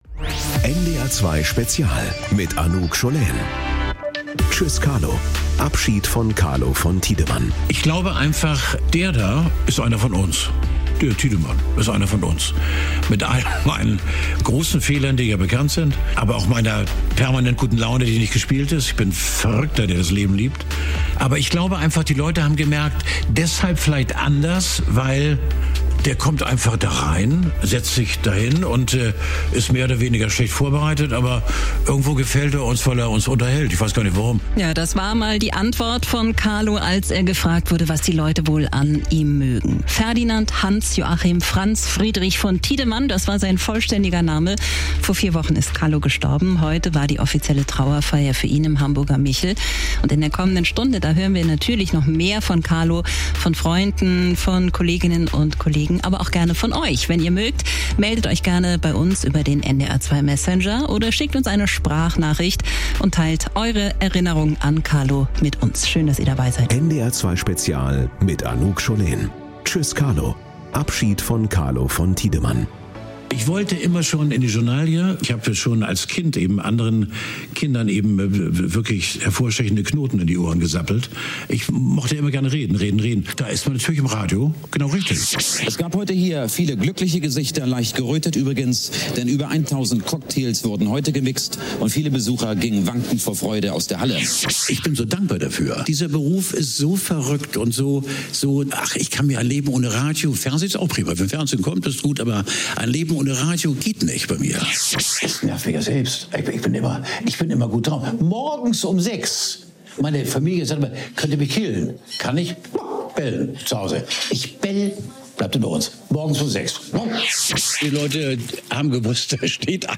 Ob politischer Machtwechsel, Orkan oder Lebensmittelskandal - NDR 2 gibt einen umfassenden Überblick und spricht mit Experten, Korrespondenten und Betroffenen.